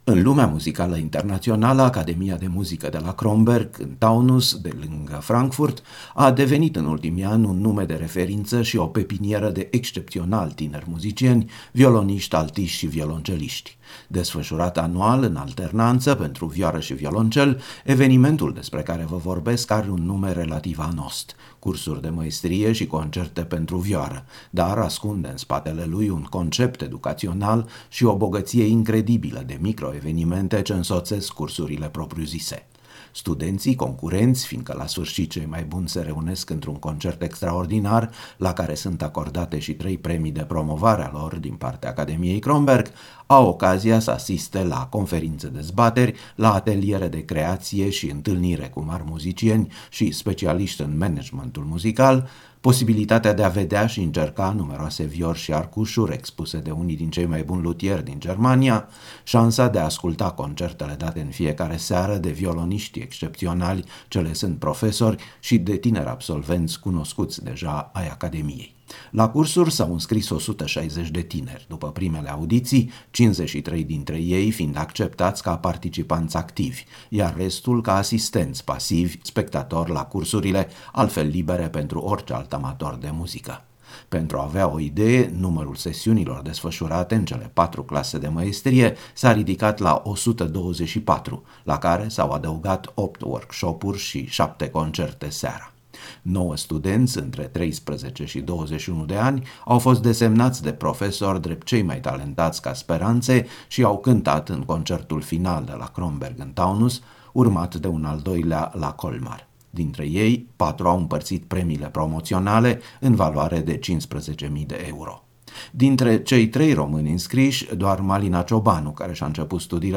Am stat de vorbă cu ea întrebînd-o cum i s-au părut cursurile la care a participat: